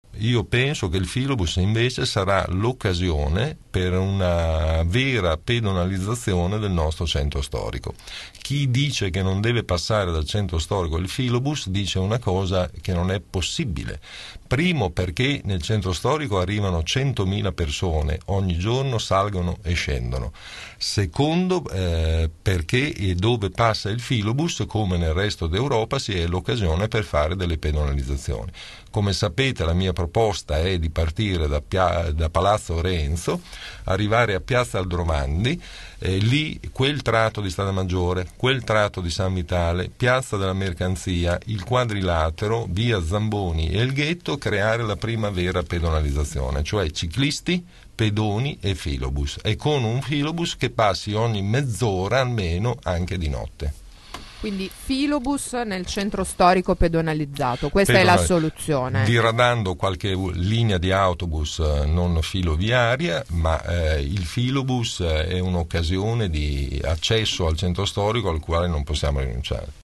Ascolta Virginio Merola ai nostri microfoni